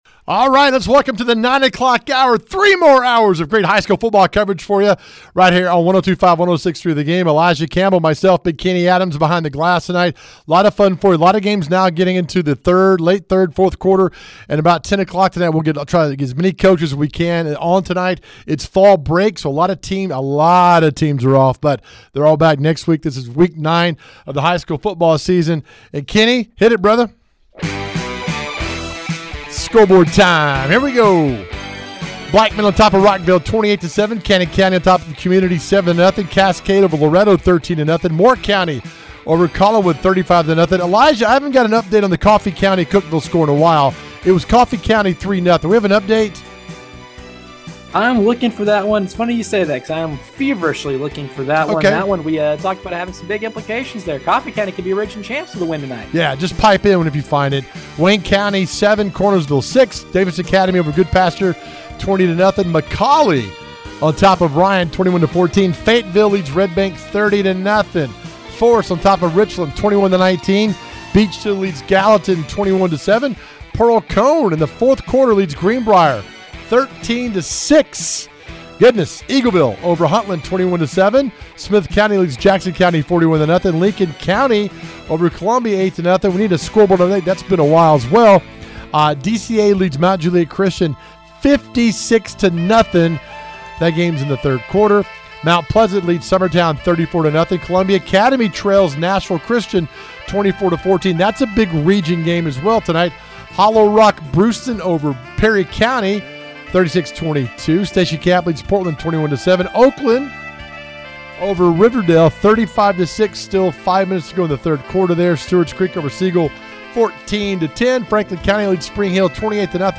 We have game updates and coaches interviews right here.